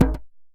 DJEM.HIT04.wav